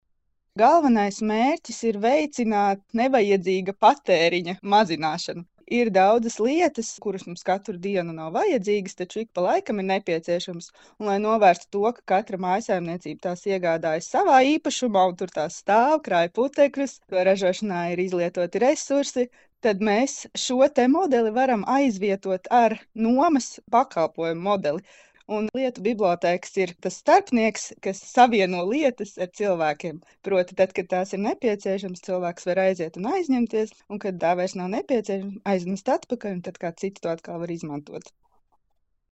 Saruna